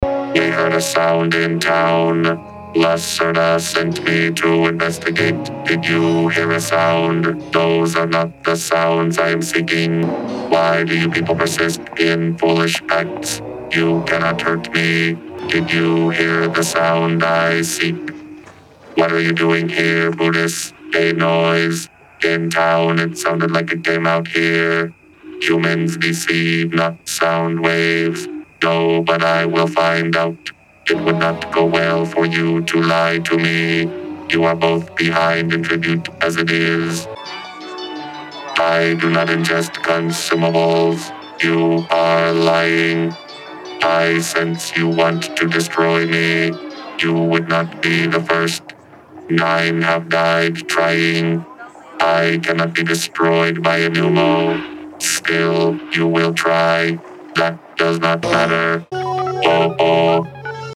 Cylon voice autopsy
How the Cylon voice build was cracked
Redeye_4-8-22_Hybrid_2x_tapeMcDSP.mp3